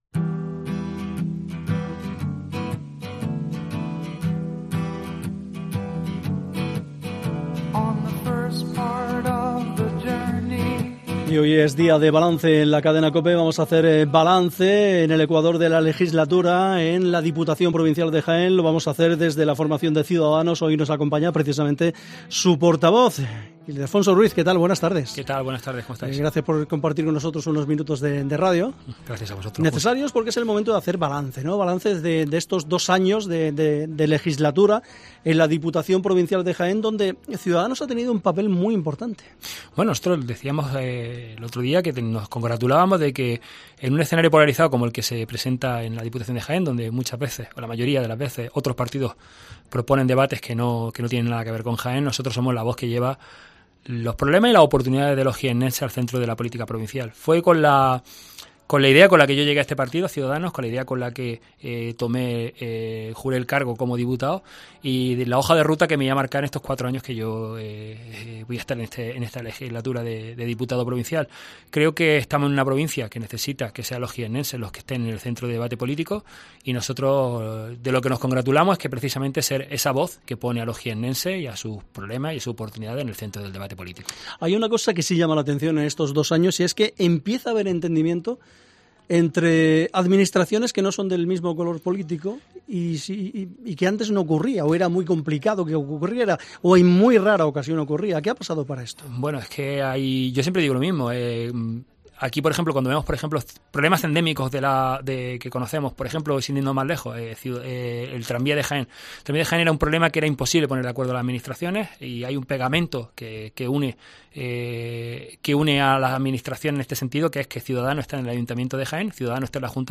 AUDIO: El portavoz de Ciudadanos (Cs) en la Diputación de Jaén, Ildefonso Ruiz, ha hecho balance en COPE Jaén del ecuador de la...